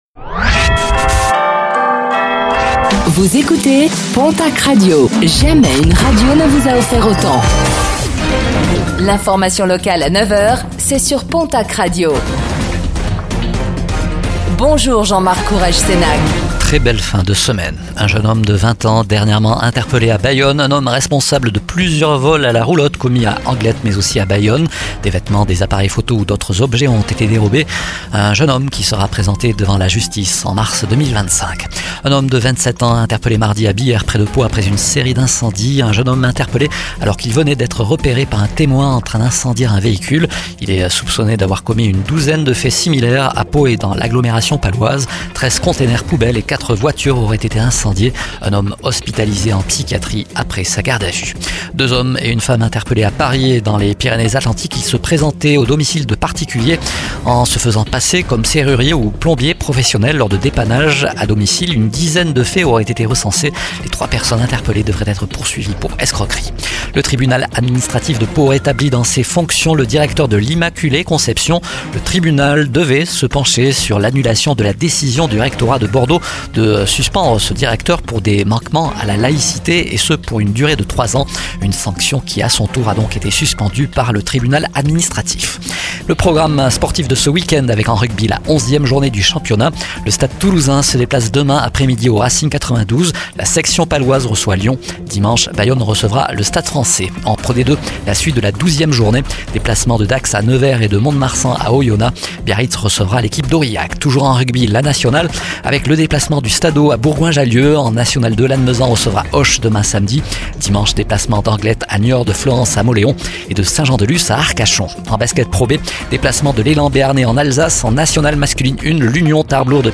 09:05 Écouter le podcast Télécharger le podcast Réécoutez le flash d'information locale de ce vendredi 29 novembre 2024